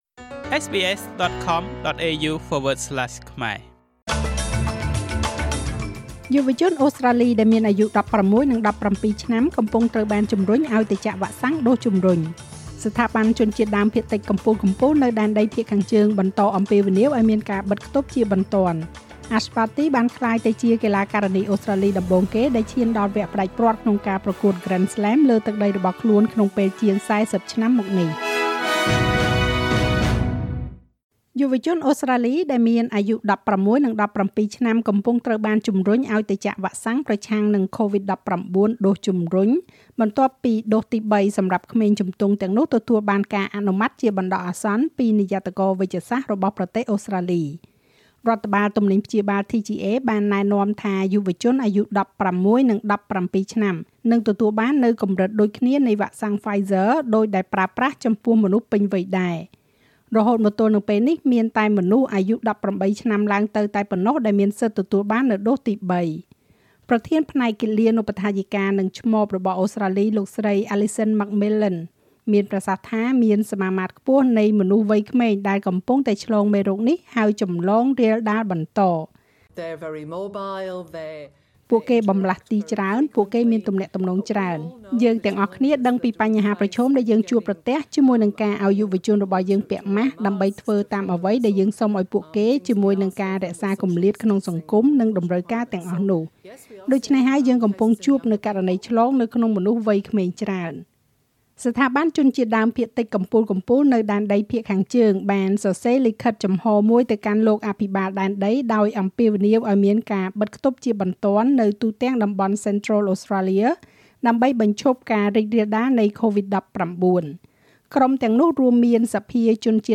ស្តាប់មាតិកាព័ត៌មានចុងក្រោយបង្អស់ក្នុងប្រទេសអូស្រ្តាលីពីវិទ្យុSBSខ្មែរ។